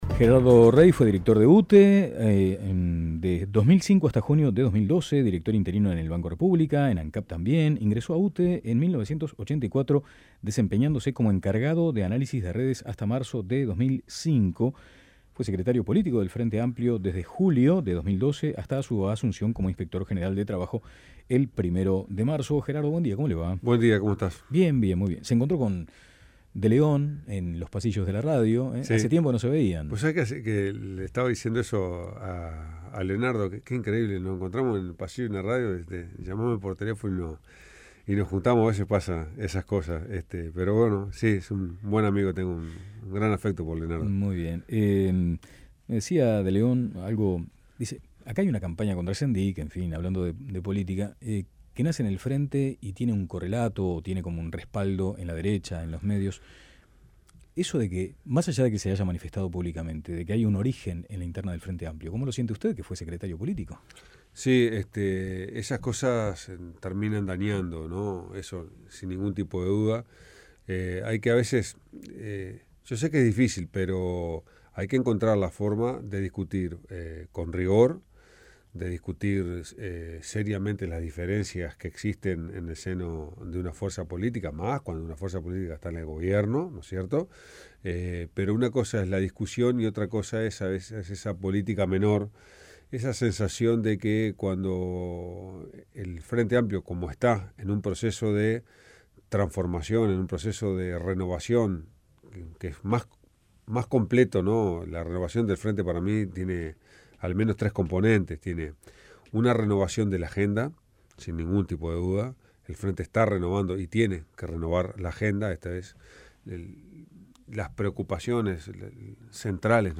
Entrevista a Gerardo Rey